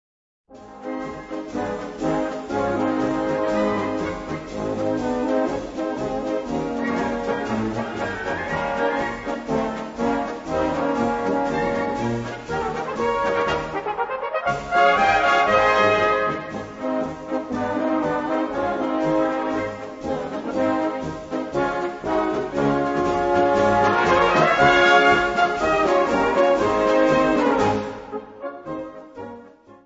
Gattung: Polka
Besetzung: Blasorchester
typisch tschechische Polkas zu schreiben.